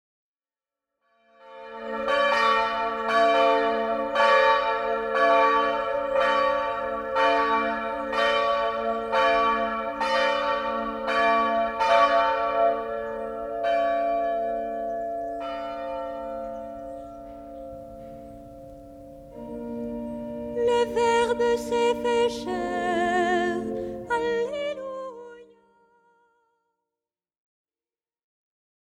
Cloches